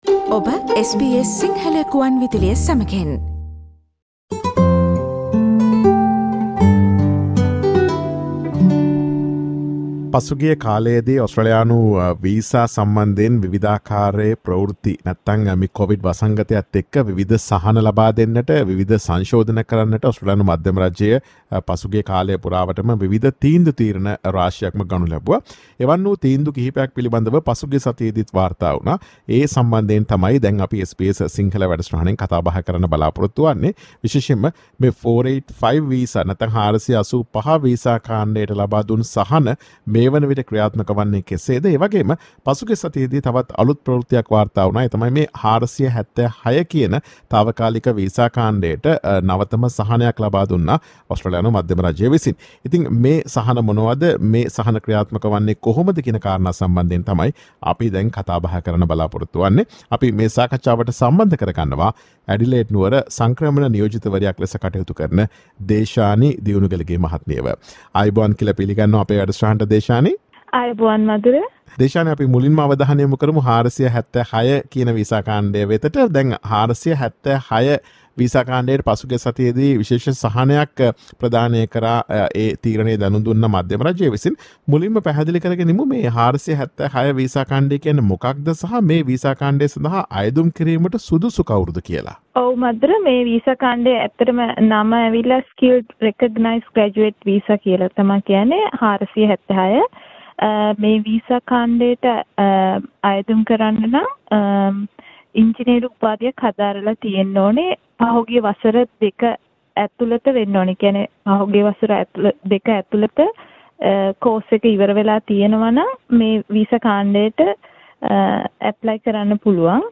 ඔස්ට්‍රේලියාවේ 476 සහ 485 වීසාවලට ලබාදුන් සහන සහ ඒවා ක්‍රියාත්මක වන්නේ කෙසේද යන්න පිළිබඳ SBS සිංහල ගුවන් විදුලිය ගෙන එන සාකච්ඡාවට සවන් දෙන්න.